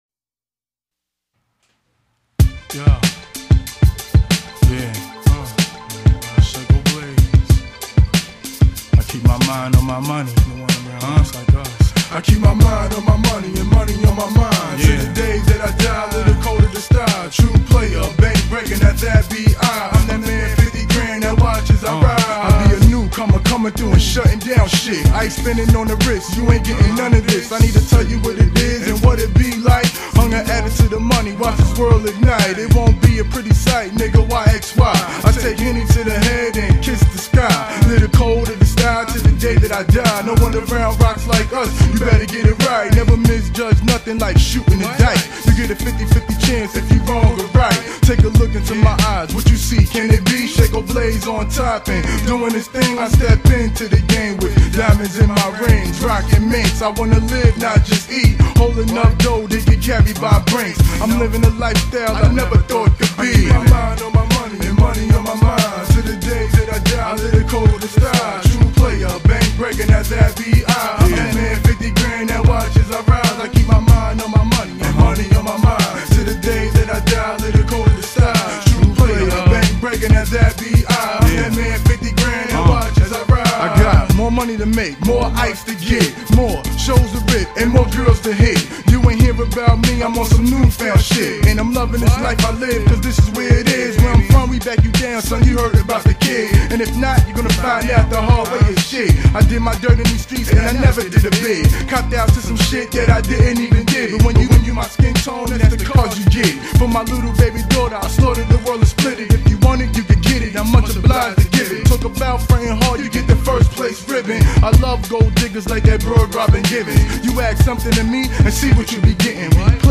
› Hip Hop Album(s